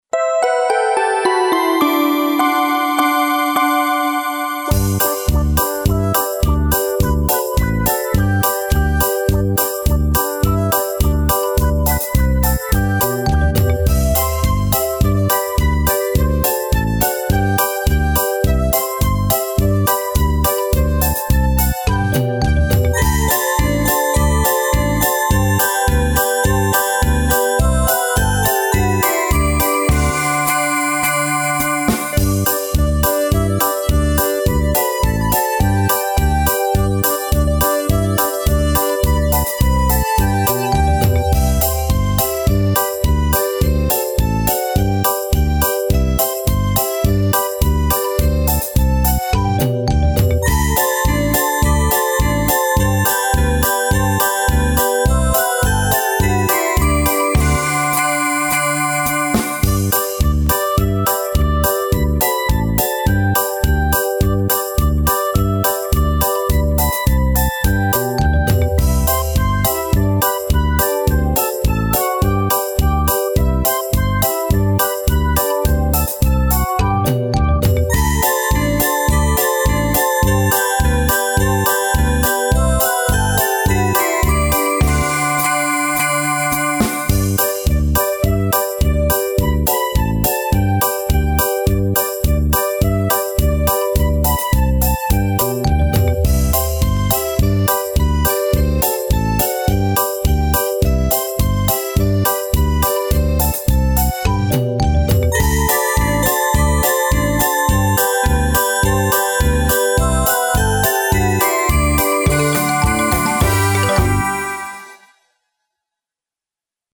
Музыка для артикуляционной гимнастики